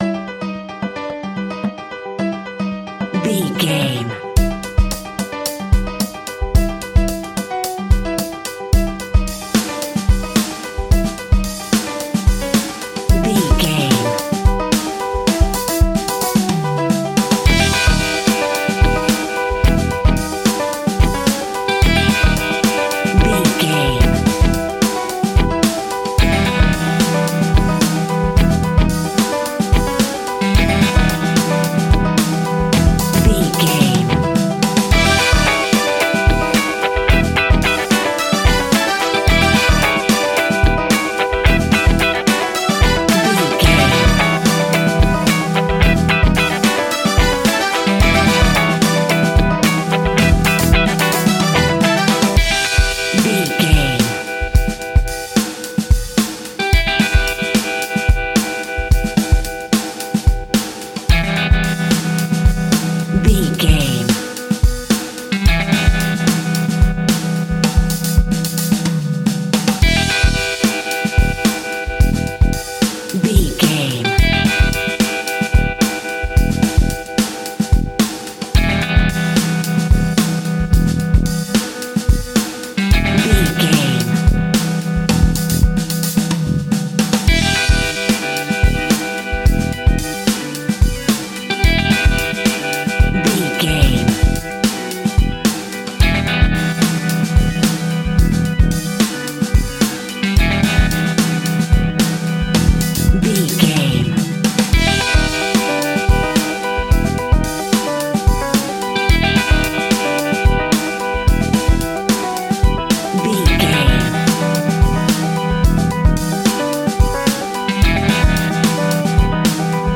Aeolian/Minor
latin
cuban music
uptempo
brass
saxophone
trumpet
fender rhodes
clavinet